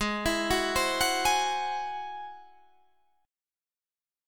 Ab+7 Chord